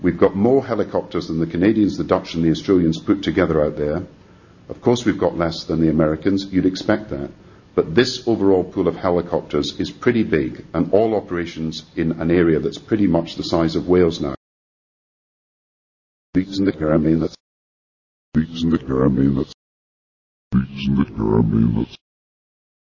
Press Conference with British PM Gordon Brown